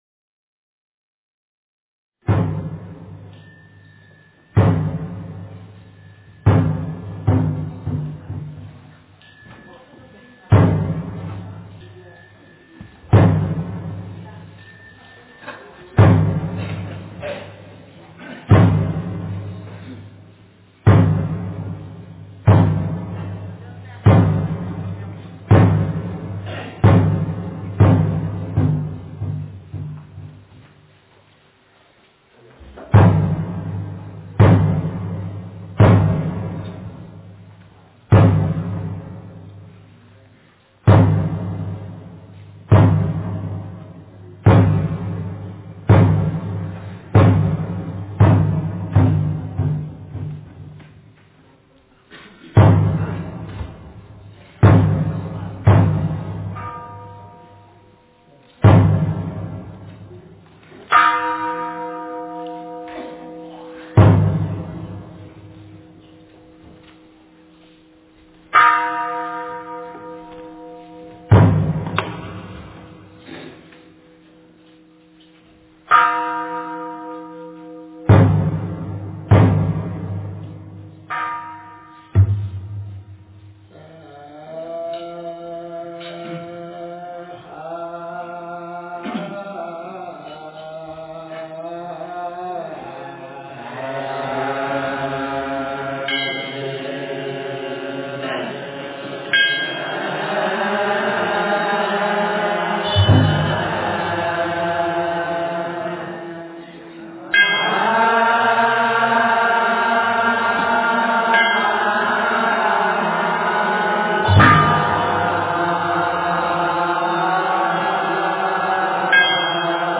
普佛(代晚课)(上)--僧团 经忏 普佛(代晚课)(上)--僧团 点我： 标签: 佛音 经忏 佛教音乐 返回列表 上一篇： 大悲咒+伽蓝赞--僧团 下一篇： 普佛(代晚课)(下)--僧团 相关文章 大乘金刚般若宝忏法卷下--金光明寺 大乘金刚般若宝忏法卷下--金光明寺...